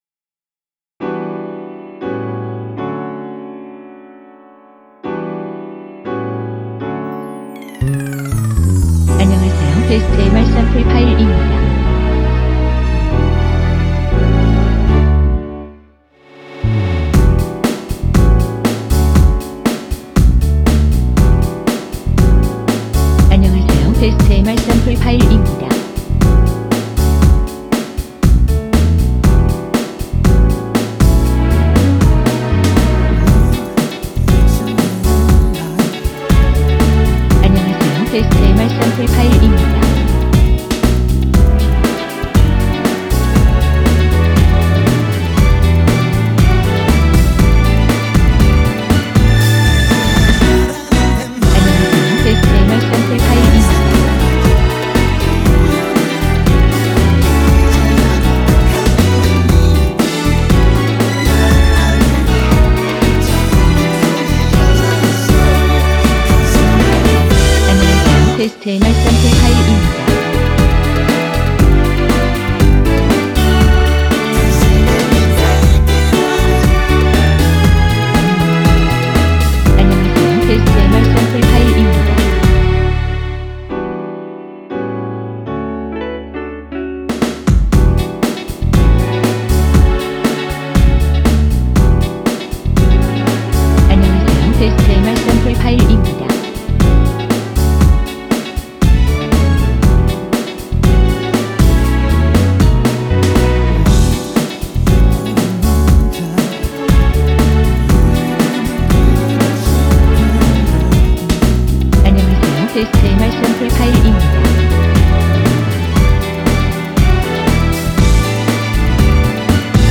사운드마커가 포함된 전체 미리듣기 입니다.
원곡의 보컬 목소리를 MR에 약하게 넣어서 제작한 MR이며